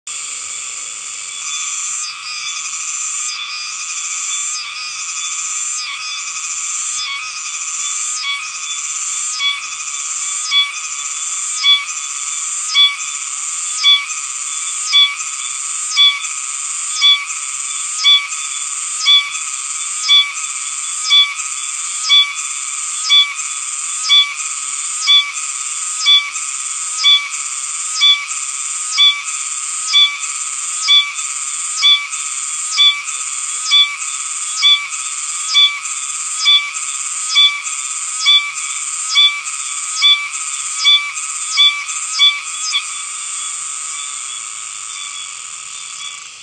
オオシマゼミの鳴き声は
奄美大島と沖縄本島 （このページでは久米島）では奄美大島の方が前奏音が長く、異なっていることが、以前から知られていました。
セミの鳴き声は前奏音、高潮音、終奏音に分かれています。 奄美大島の個体は４段階、久米島の個体は３段階に分かれています。
しかし、奄美大島の個体の方が弱音が 目立ち、テンポもややゆっくりしています。